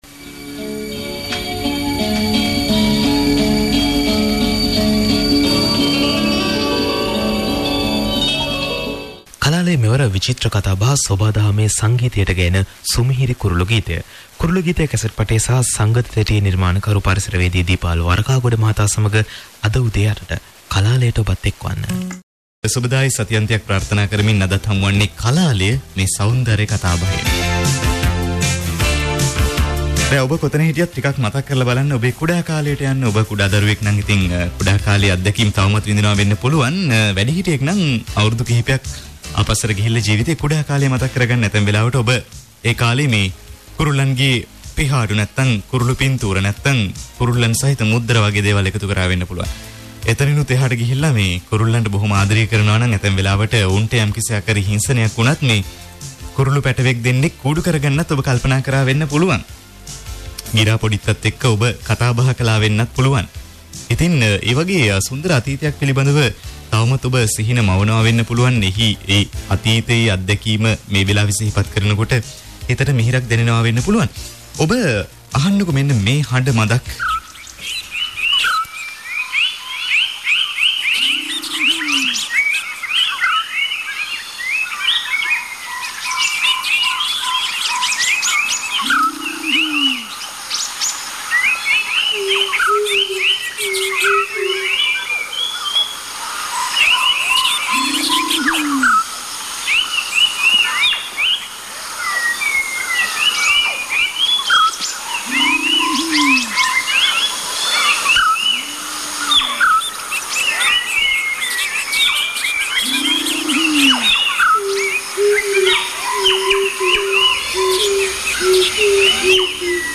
Asura FM radio interview in November 2005 [in Sinhala], on Sir Lanka Bird Sounds CD and audio cassette tape
Asura-FM-radio-interview_in-November-2005.mp3